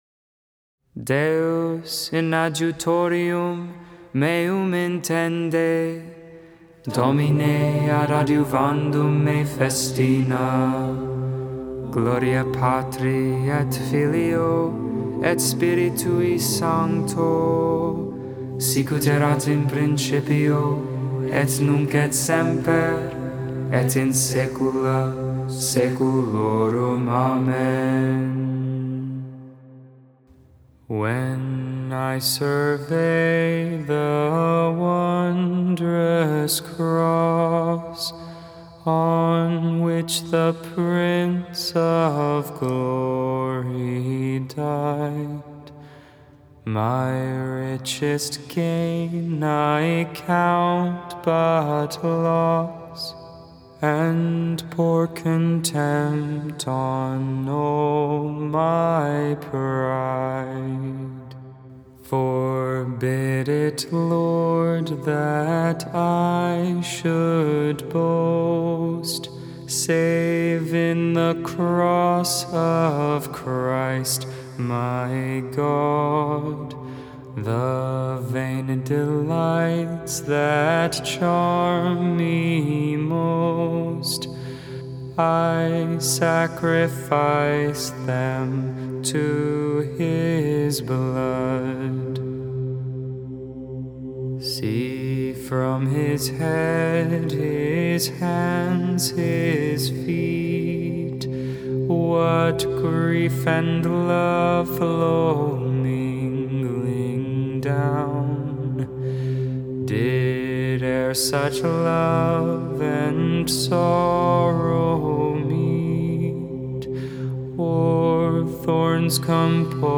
Vespers, Evening Prayer for Monday of Holy Week.